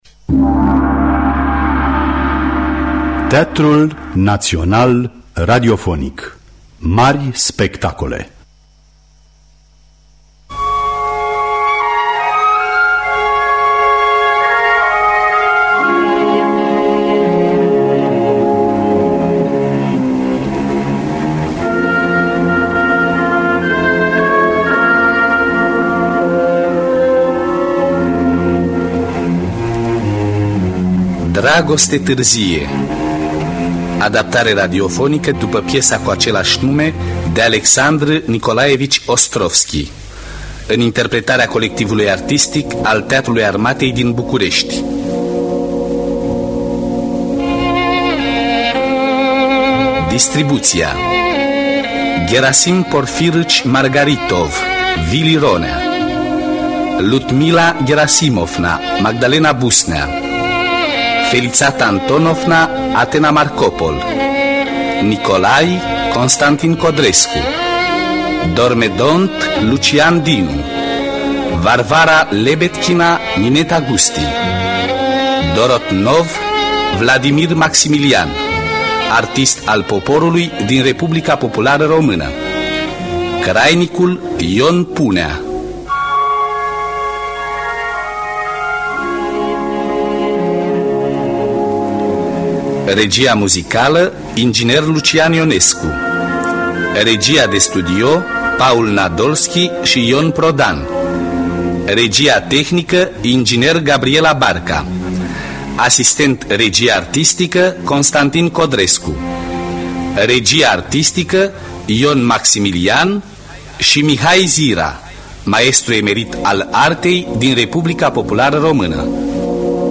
Dragoste târzie de Alexandr Nicolaevici Ostrovski – Teatru Radiofonic Online